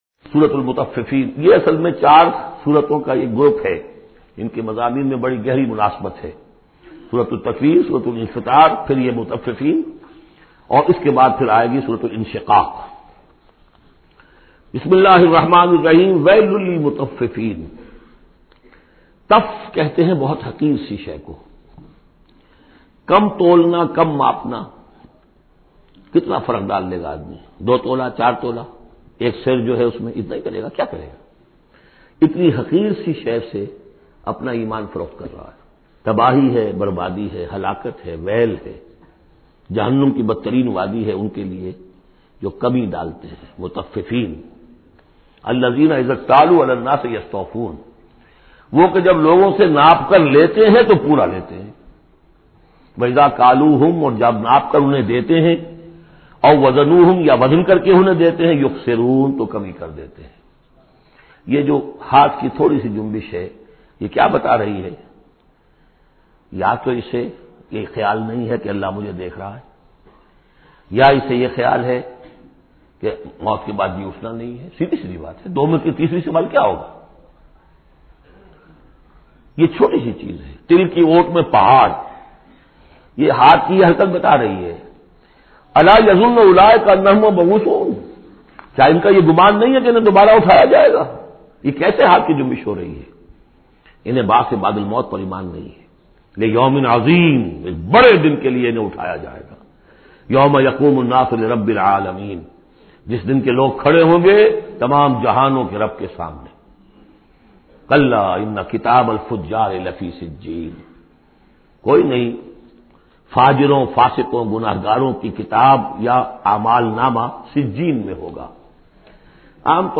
Surah Mutaffifin Tafseer by Dr Israr Ahmed
Surah Mutaffifin, listen online mp3 urdu tafseer in the voice of Dr Israr Ahmed.